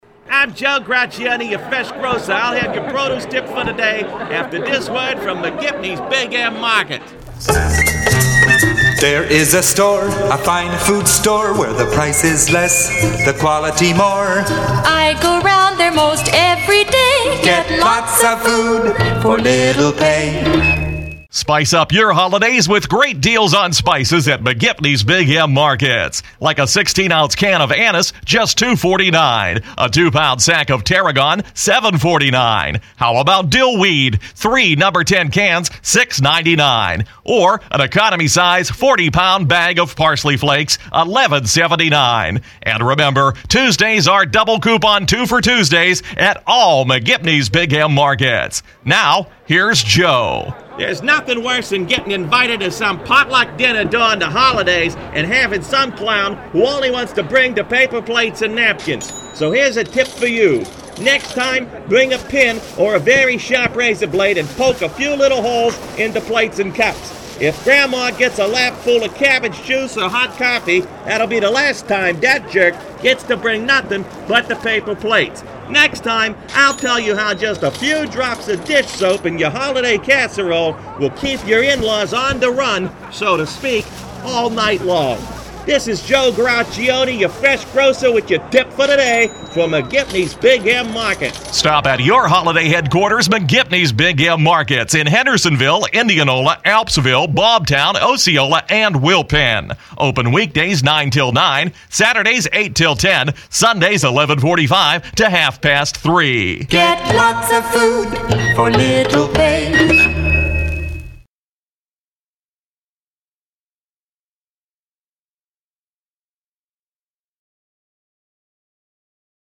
In lieu of any real content today, here's some alleged humor from my so-called "performances" in the stagnant backwaters of local radio: